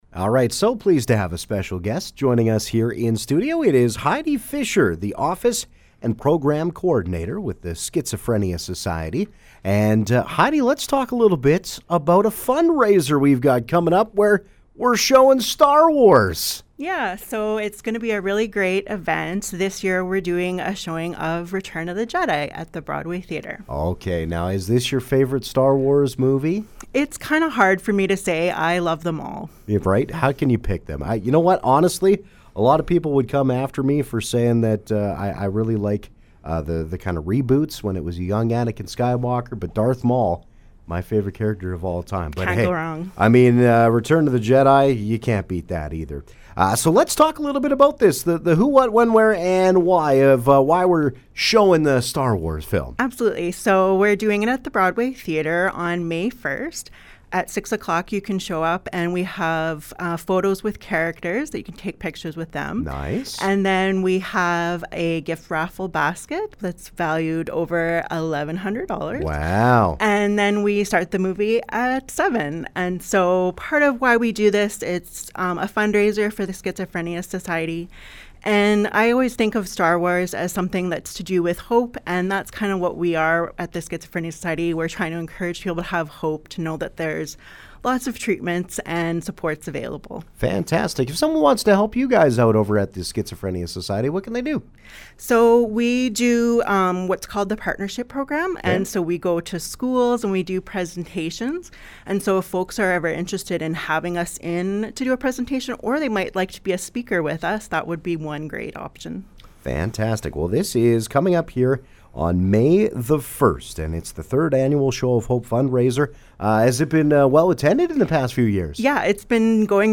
Interview: Schizophrenia Society Show of Hope
starwars-fundraiser-interview.mp3